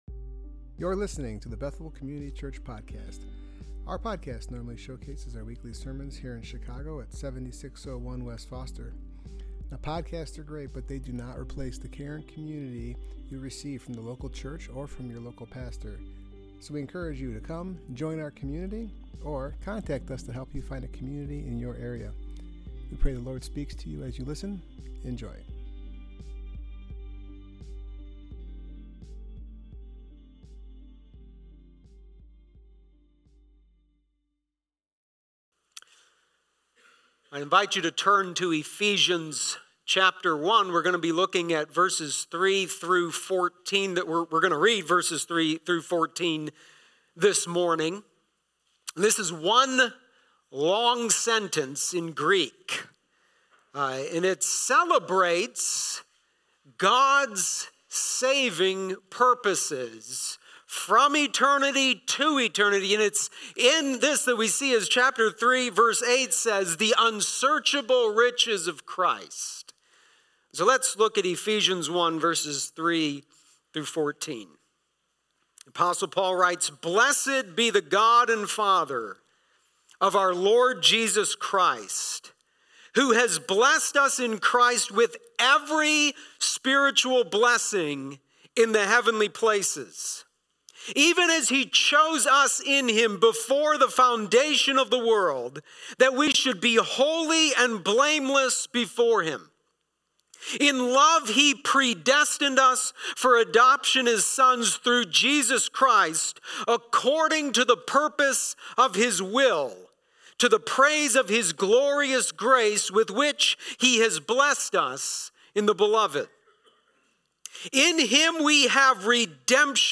Union With Christ Passage: Ephesians 1:3-6 Service Type: Worship Gathering « Abundant